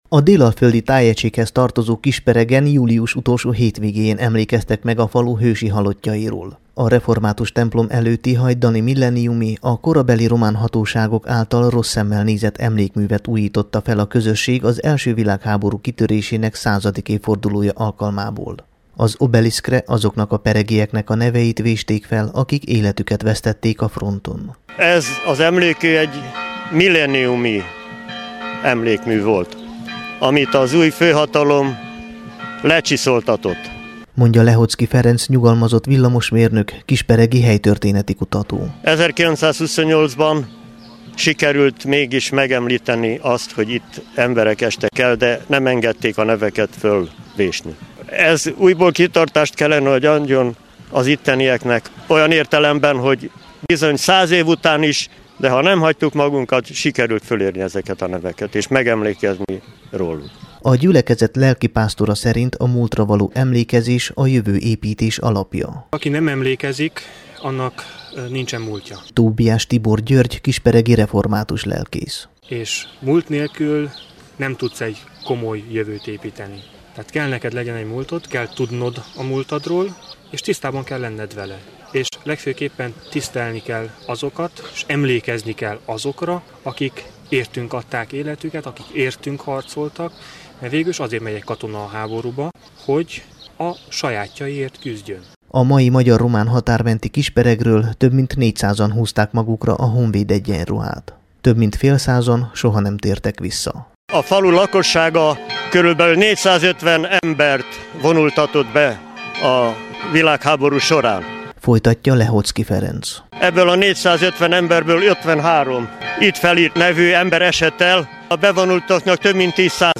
Kisperegi_elso_vilaghaborus_megemlekezes.mp3